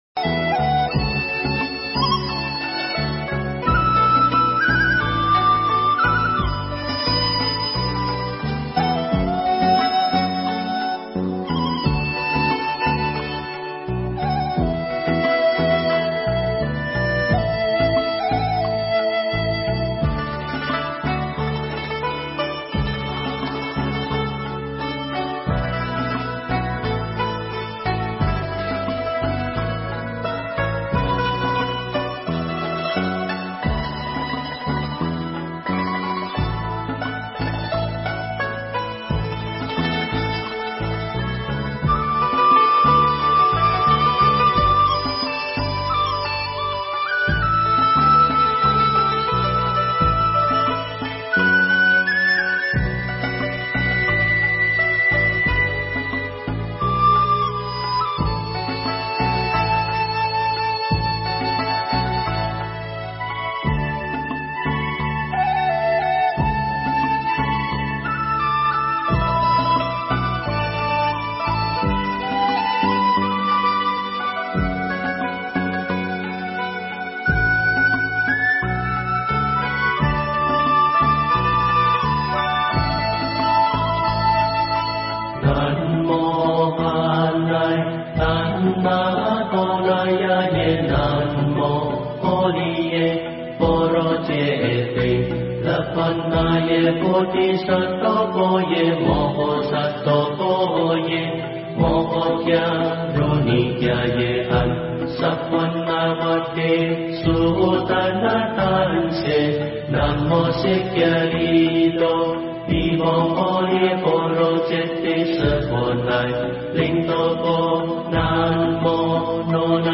Mp3 thuyết pháp Ý Nghĩa Thiền Và Niệm Phật Ứng Dụng Trong Cuộc Sống